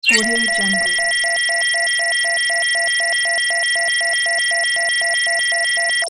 دانلود افکت صدای آژیر خطر کوتاه
افکت صدای آژیر خطر یک گزینه عالی برای هر پروژه ای است که به صداهای هشدار دهنده و جنبه های دیگر مانند زنگ خطر و آژیر هشدار نیاز دارد.
Sample rate 16-Bit Stereo, 44.1 kHz
Looped No